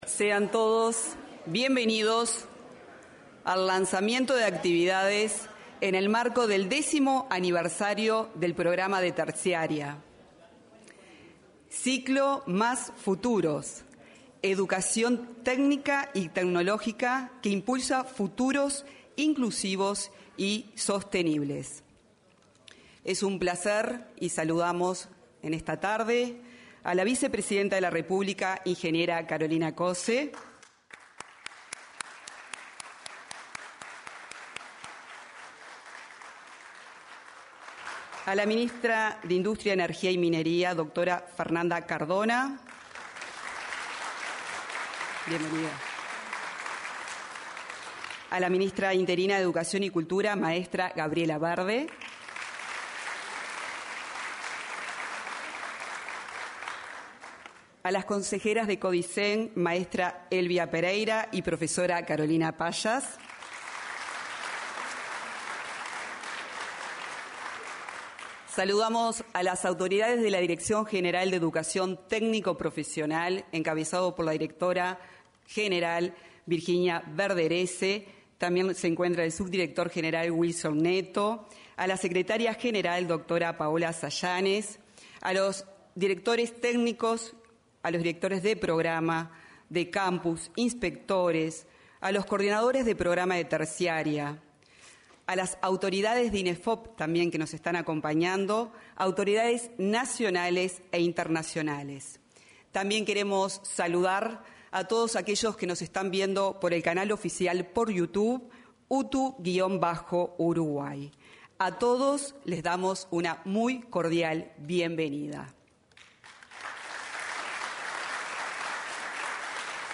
Apertura del 10.° aniversario del Programa de Educación Terciaria de UTU 04/09/2025 Compartir Facebook X Copiar enlace WhatsApp LinkedIn Se conmemoró el 10.° aniversario del Programa de Educación Terciaria de la Dirección General de Educación Técnico Profesional (DGETP-UTU).